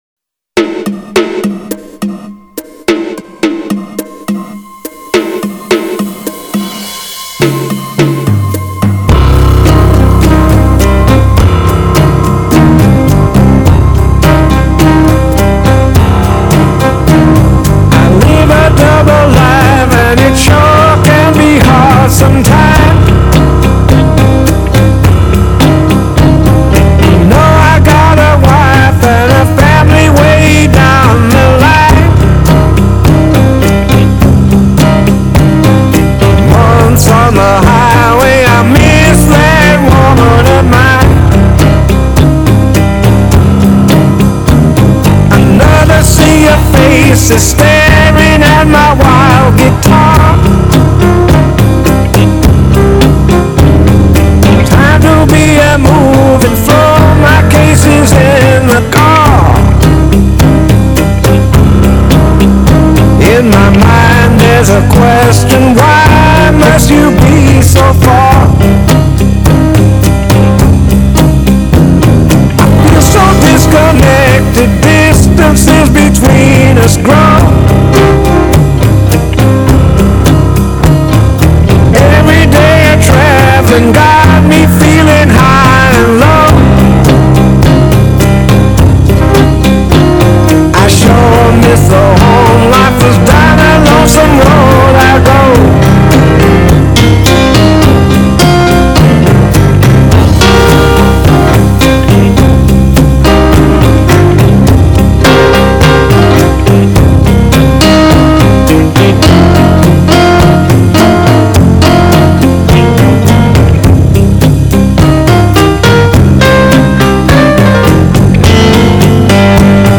А недели две назад случилась просто катастрофа - решил попробывать прогнать через эту программу собираемый более пяти лет сборник любимых блюзов - около двухсот треков. После обработки программой более половины треков захрипели и никакими ухищерениями не желают возвращаться в первоначальное состояние.